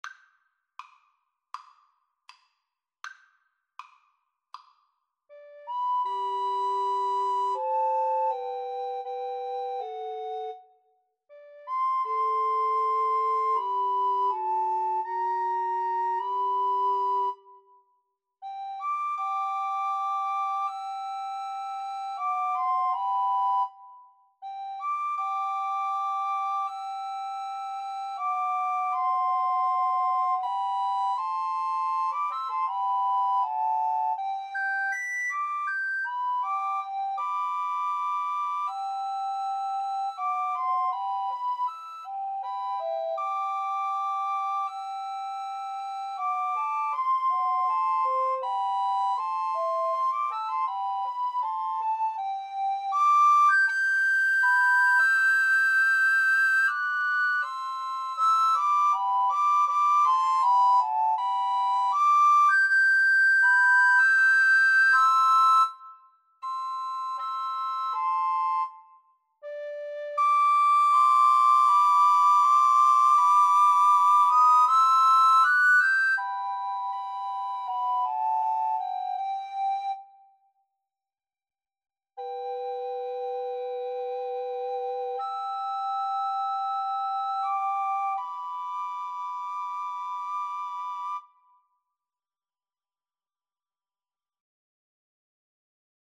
G major (Sounding Pitch) (View more G major Music for Recorder Trio )
Andante